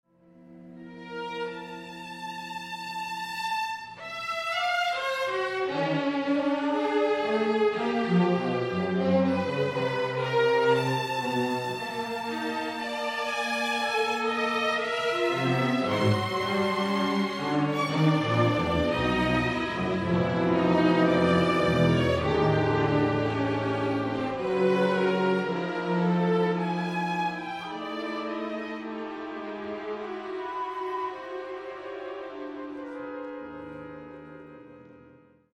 Symphonisches Gedicht für Orchester